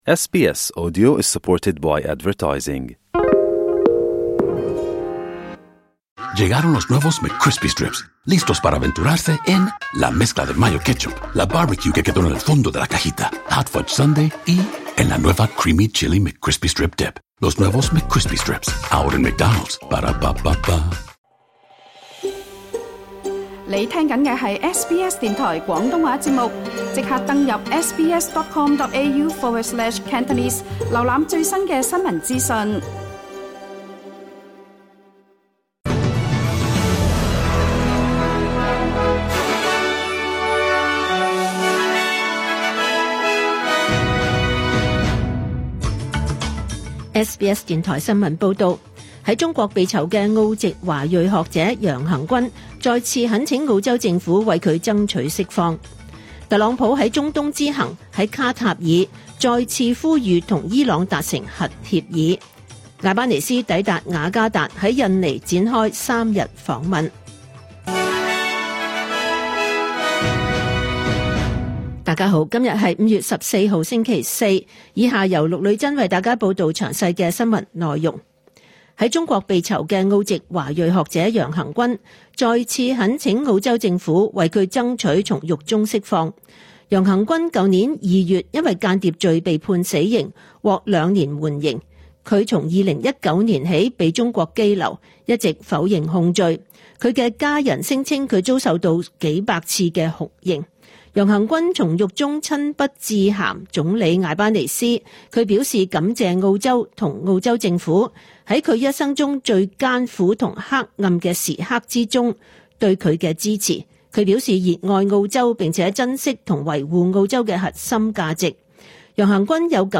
2025 年 5 月 15 日 SBS 廣東話節目詳盡早晨新聞報道。